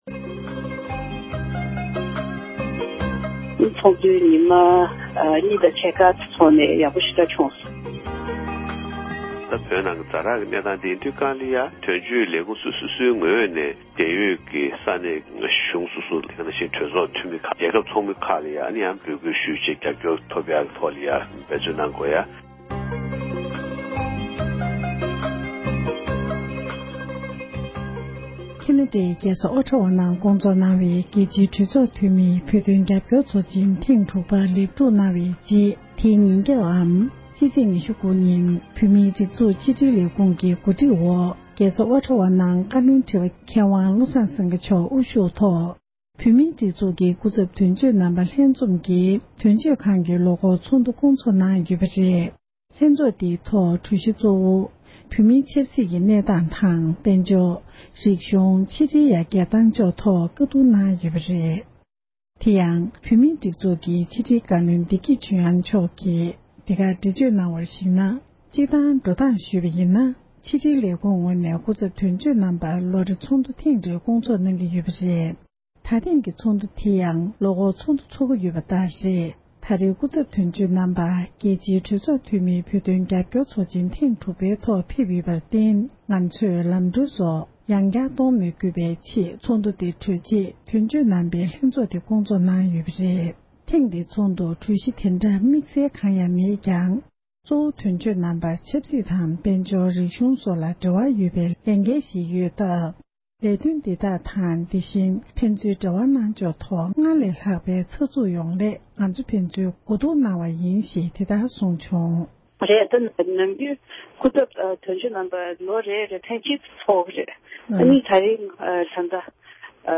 འབྲེལ་ཡོད་མི་སྣར་བཀའ་འདྲི་ཞུས